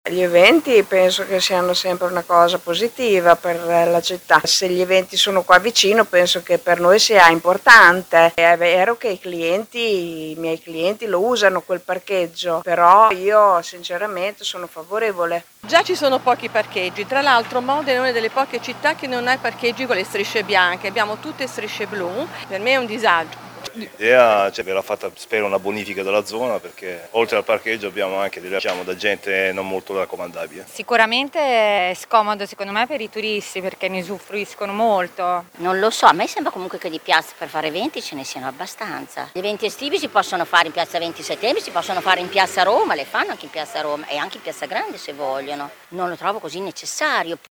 VOX-PARCHEGGIO-AGO.mp3